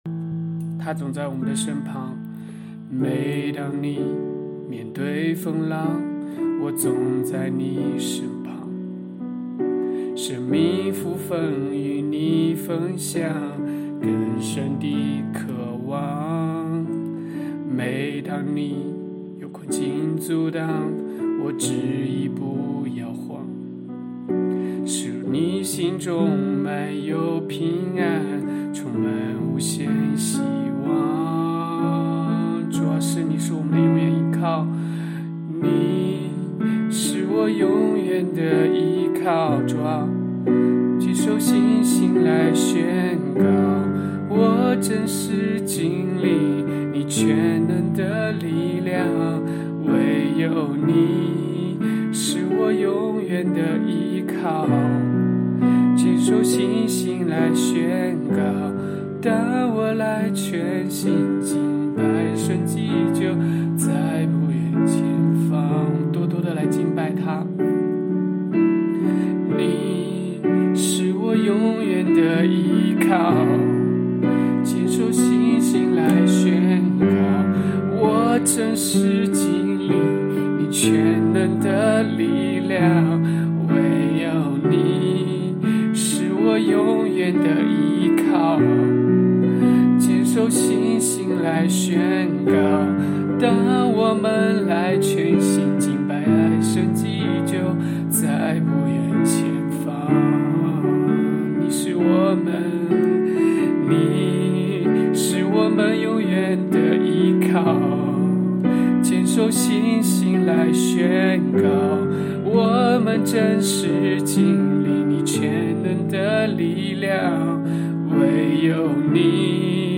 HAKA祷告敬拜MP3 启示性祷告： 持续祷告：祈求神的旨意成就在我们的身上，带领做新事！